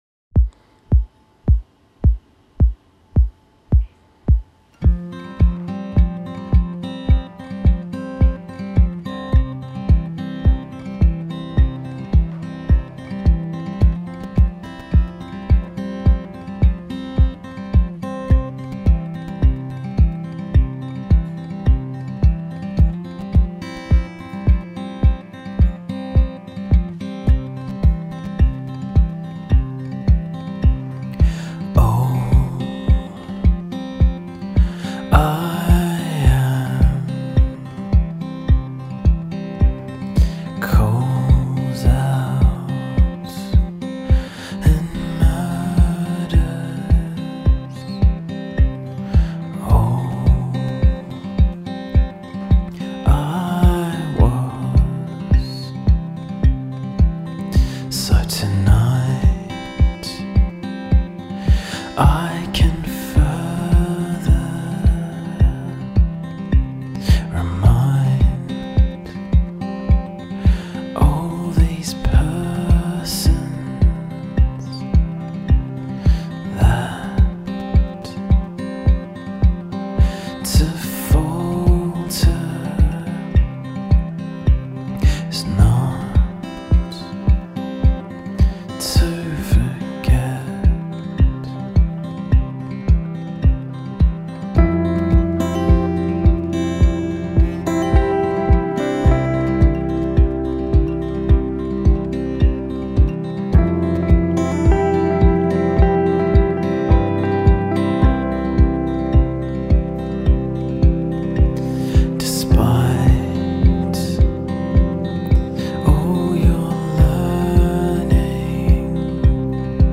duo